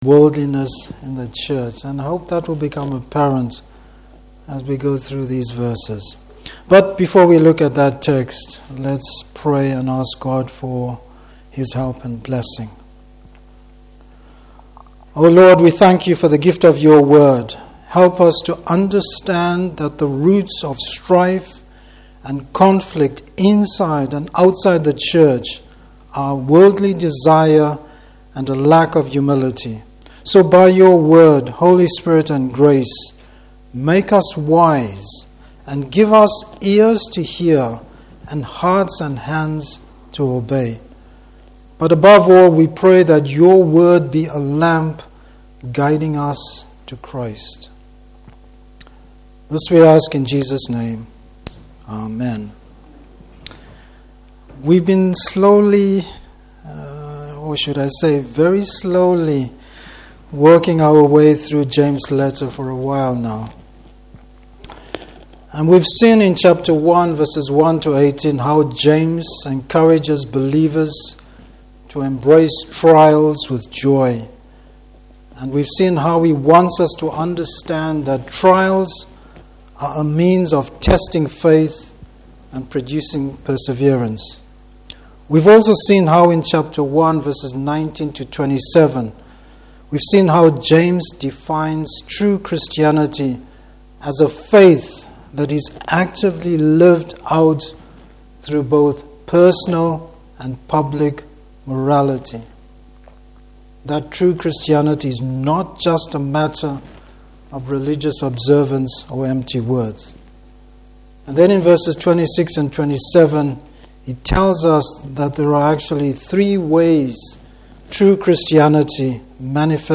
Service Type: Morning Service Keeping the Church separate from the world.